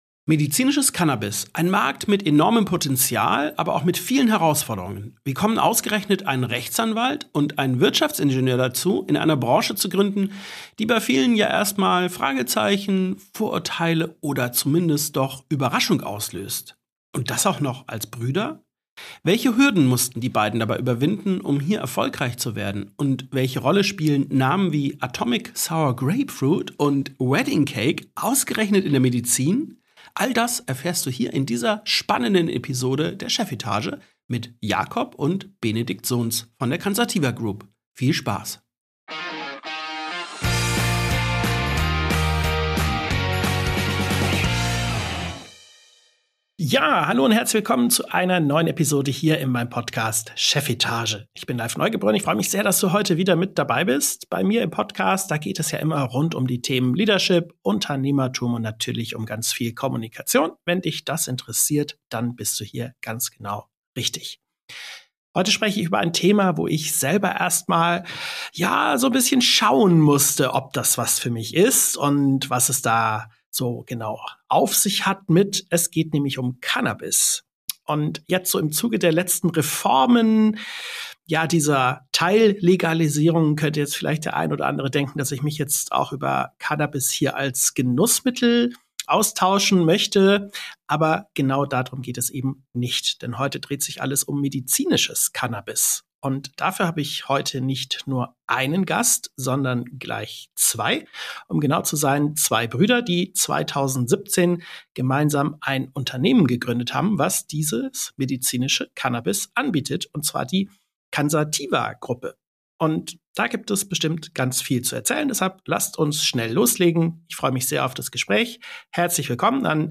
69 ~ Chefetage - CEOs, Unternehmer und Führungskräfte im Gespräch Podcast
In dieser Folge gibt es eine Premiere: Zum ersten Mal sind gleich zwei Gäste dabei.